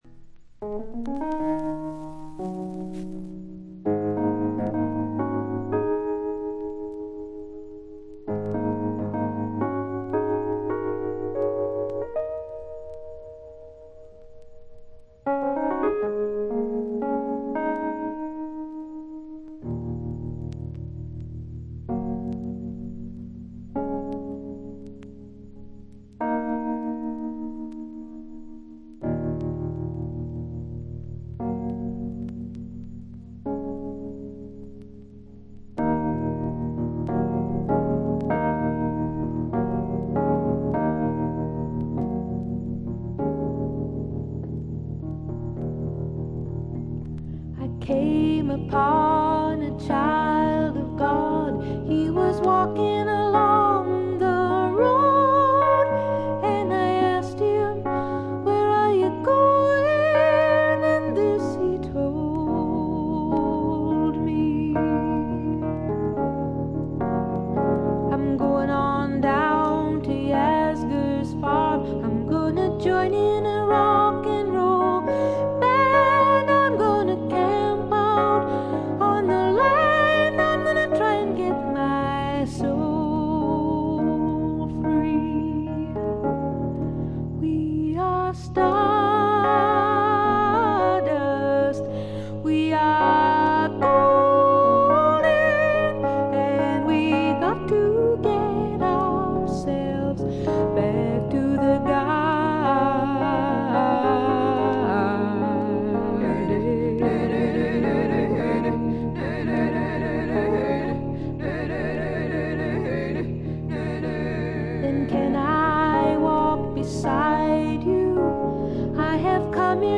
軽微なバックグラウンドノイズ、チリプチ。散発的なプツ音少し。
試聴曲は現品からの取り込み音源です。